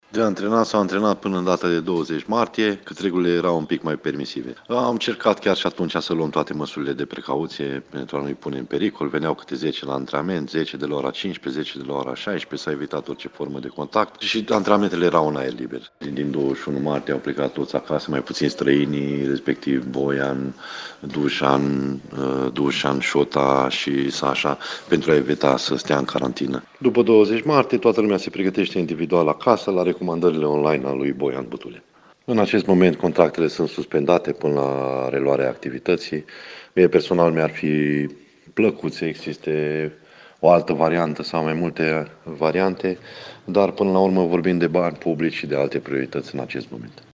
a vorbit, pentru Radio Reșița, despre perioada trecută de la ultimul antrenament în Sala Polivalentă, dar și despre decizia clubului de a suspenda contractele tuturor celor implicați.